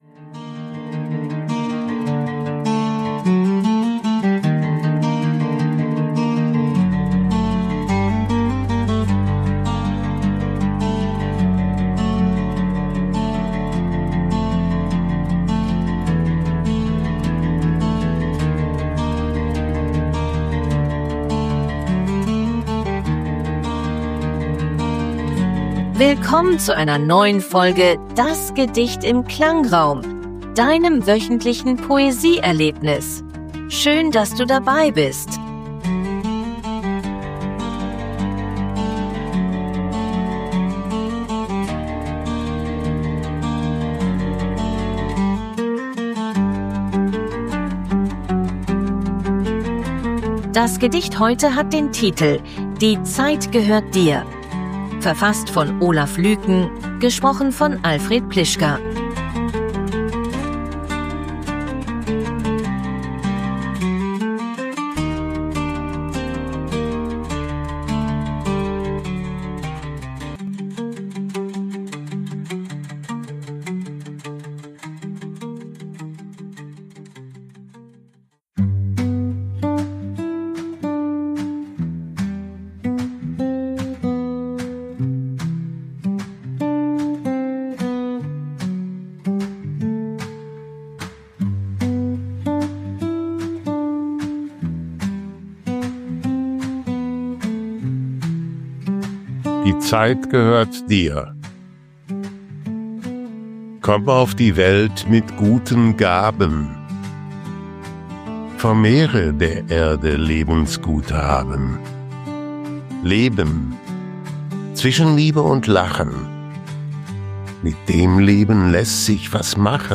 eines sorgfältig ausgewählten Gedichts, umrahmt von einer
stimmungsvollen Klangkulisse.
begleitet von KI-generierter Musik.